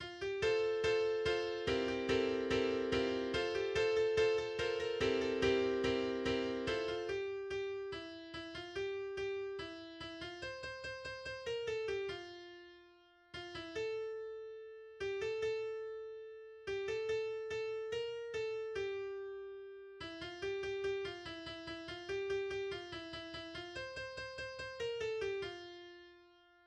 \tempo 4 = 144
\time 4/4
\key f \major